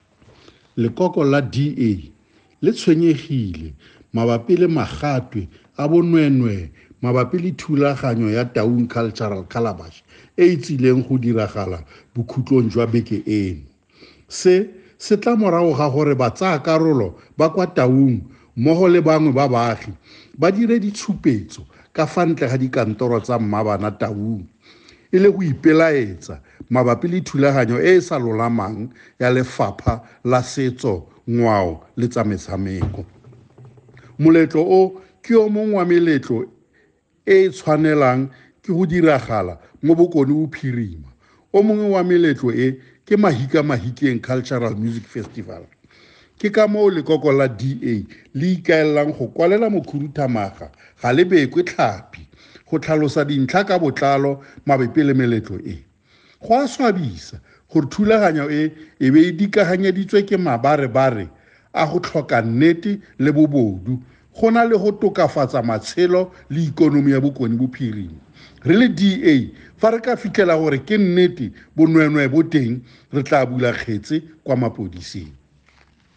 Note to Editors: Please find attached soundbites in
Setswana by Winston Rabotapi MPL.